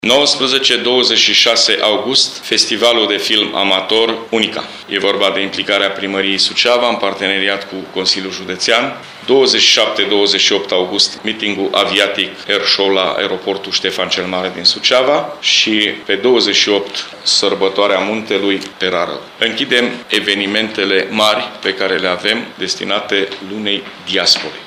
Președintele CJ Suceava, Gheorghe Flutur, detaliază alte manifestări din a doua jumătate a lunii august.